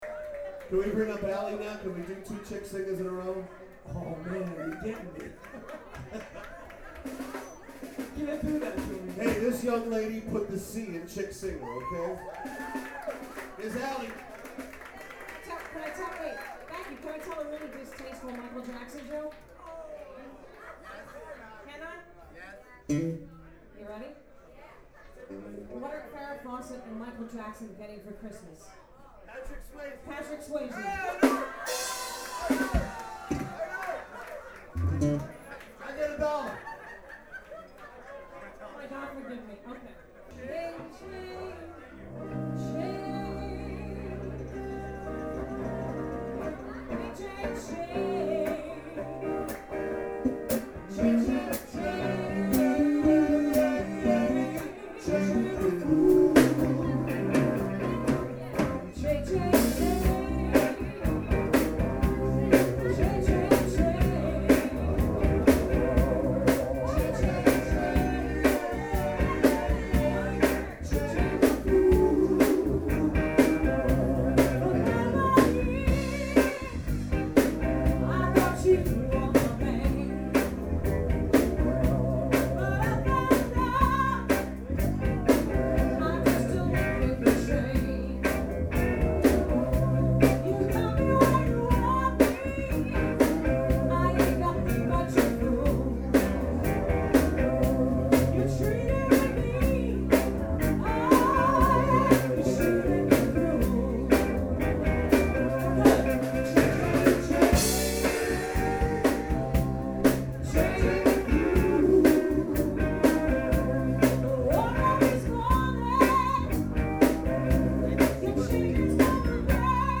SPECIAL JAM